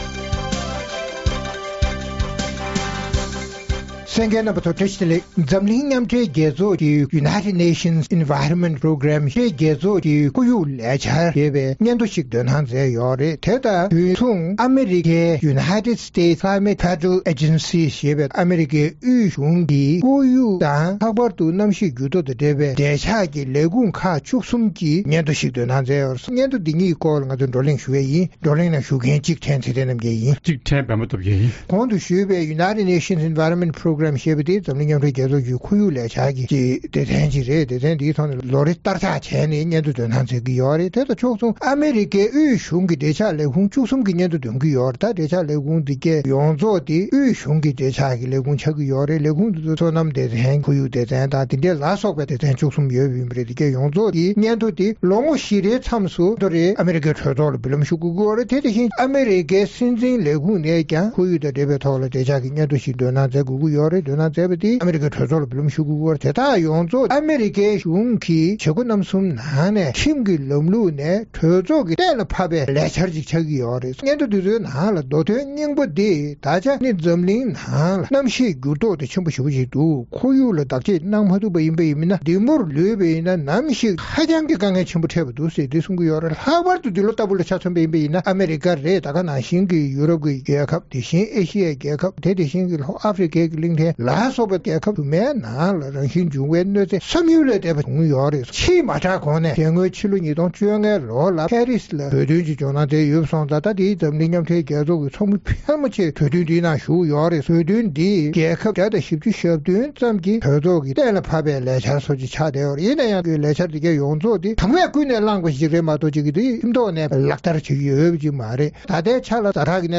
རྩོམ་སྒྲིག་པའི་གླེང་སྟེགས་ཞེས་པའི་ལེ་ཚན་ནང་། འཛམ་གླིང་མཉམ་སྦྲེལ་རྒྱལ་ཚོགས་ཀྱི་ཁོར་ཡུག་ལས་འཆར་སྡེ་ཚན་དང་། རྒྱལ་སྤྱིའི་གནམ་གཤིས་བསྒྱུར་ལྡོག་ལྷན་ཚོགས། ཨ་རི་གཞུང་གི་གནམ་གཤིས་བརྟག་དཔྱད་ཚན་པ་བཅས་ཀྱིས་བཏོན་པའི་འདི་ལོའི་གནམ་གཤིས་སྙན་ཐོའི་ནང་དོན་སྐོར་རྩོམ་སྒྲིག་འགན་འཛིན་རྣམ་པས་བགྲོ་གླེང་གནང་བ་གསན་རོགས་གནང་།